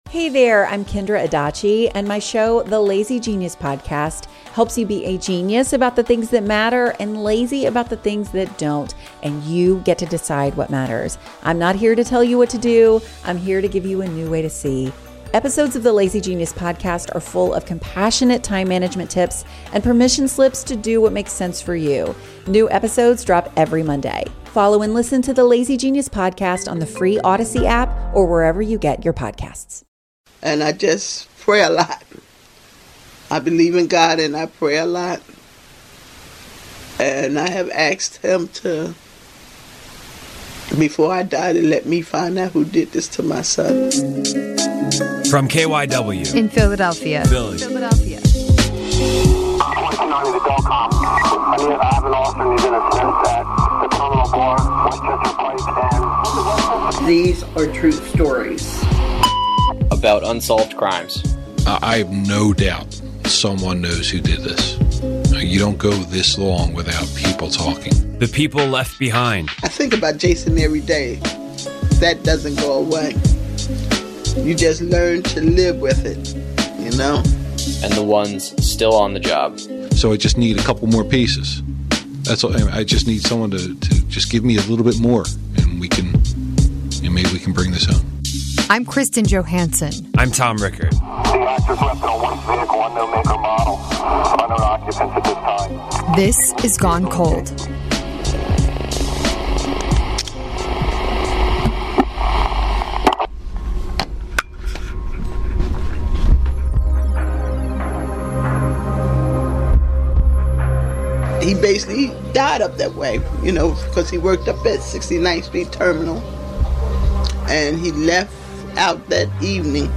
in the KYW Studios in Philadelphia.